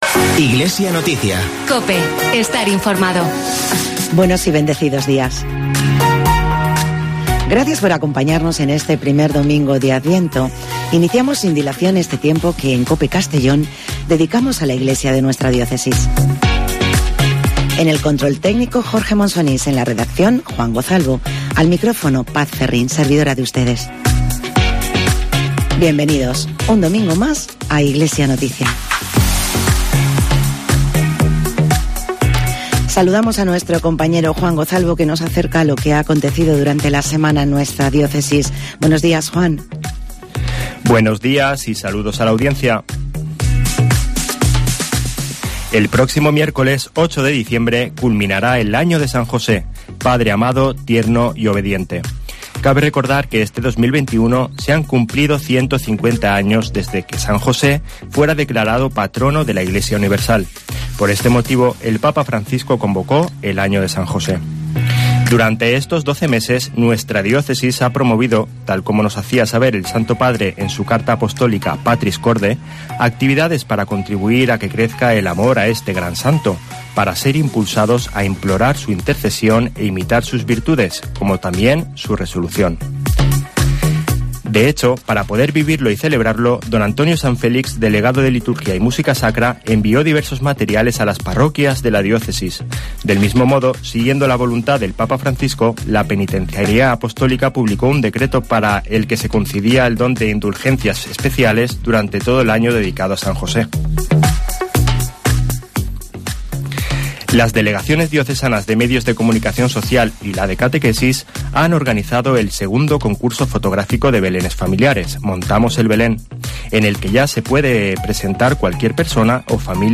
Espacio informativo